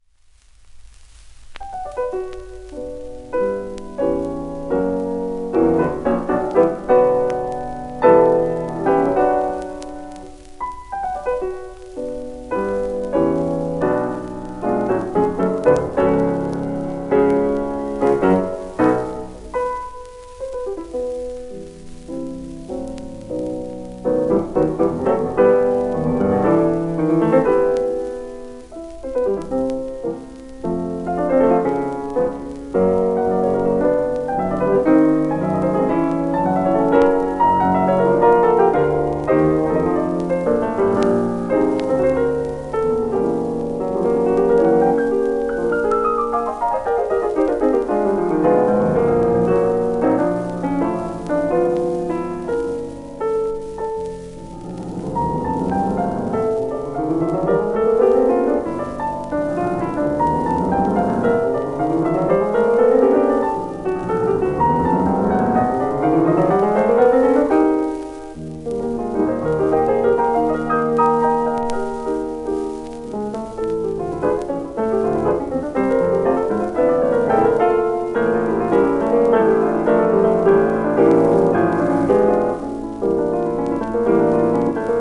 1951年録音